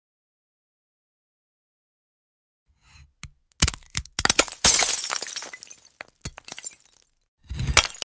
Cutting a Glass AK 47 Redline sound effects free download
Cutting a Glass AK-47 Redline 🔪 | CS2 ASMR Skin Shatter